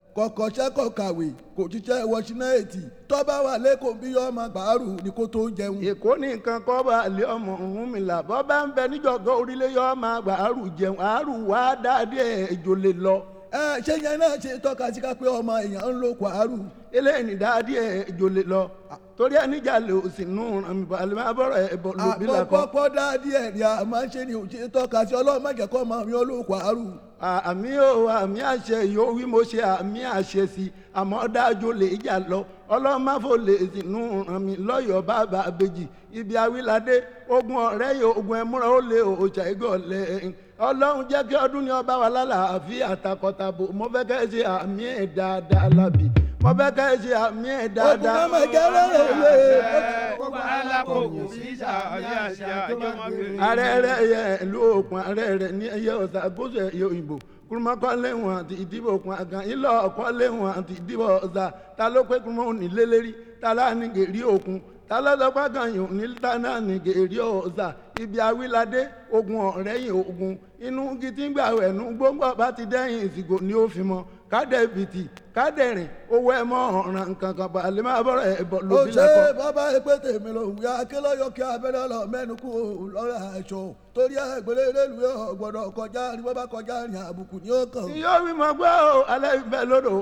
media : VG+/VG+(細かいスリキズによるわずかなチリノイズ/軽いプチノイズが入る箇所あり)
その独特の節回しによるチャントとトーキング・ドラムによる疾走感のあるビートが交互に訪れる展開など